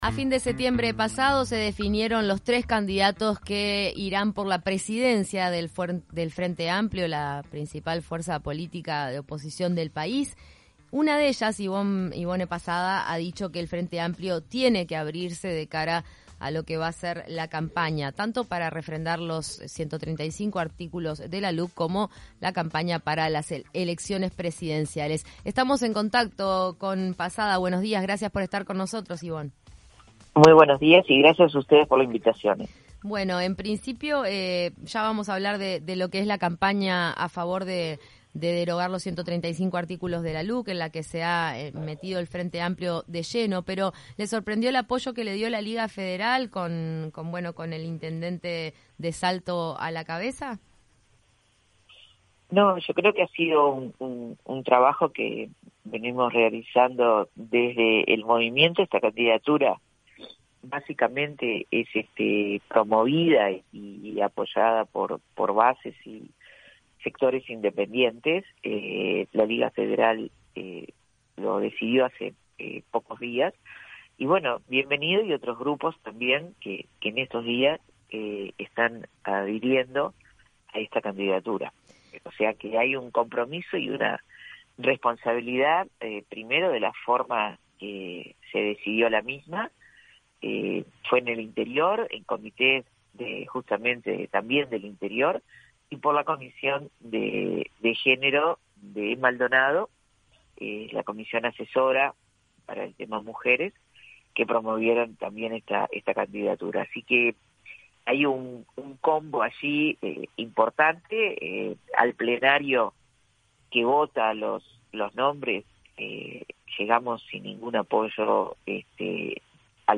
En entrevista con 970 Noticias Primera Edición consideró que su candidatura es la más cercana al interior.